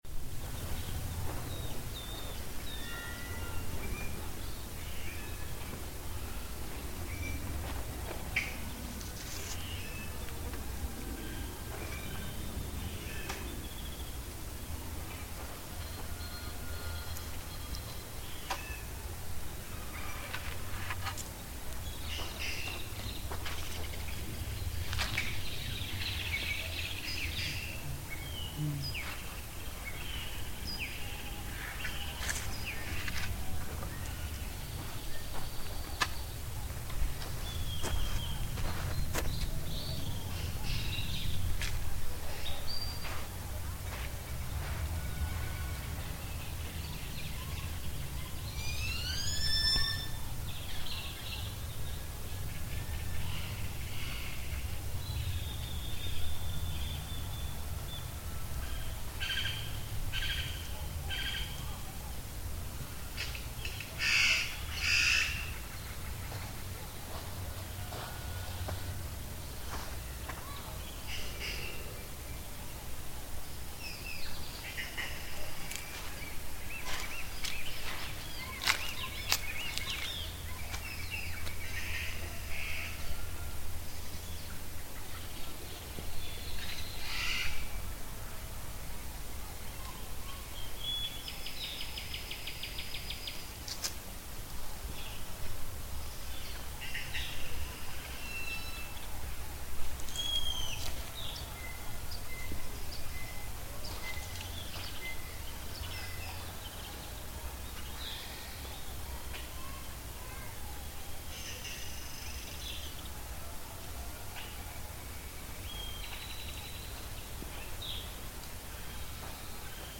Es epoca de estiaje y debido a las altas temperaturas el nivel del río se encuentra bajo, los árboles hospedan una gran cantidad de aves que intentamos identificar con nuestros precarios conocimientos al respecto:
1.- Zanate 2.- Pijui 3.- Zenzontle 4.- Pájaro carpintero (Copete rojo)
* Se aconseja el uso de audífonos para una mejor experiencia sonora Autor
Equipo: Micrófonos binaurales de construcción casera, si desea construir los suyos aquí encuentra las instrucciones . Grabadora Sony ICD-UX80 Stereo.